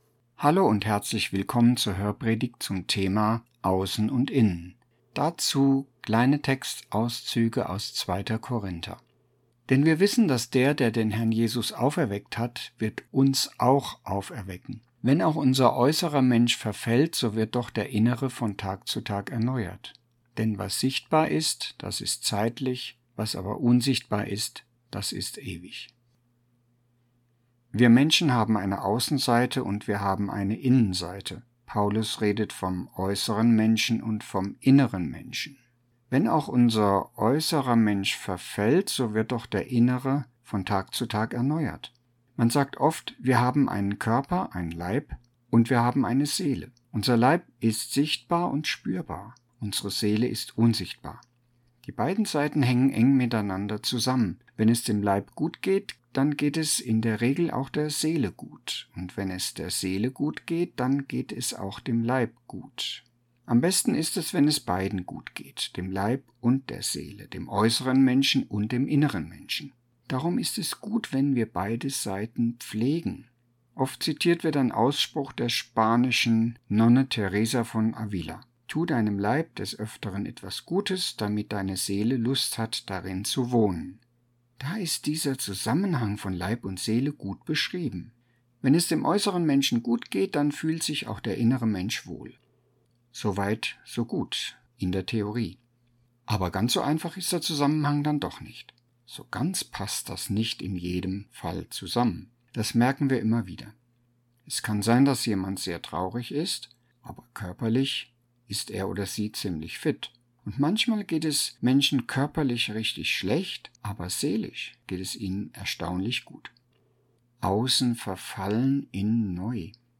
Hörpredigten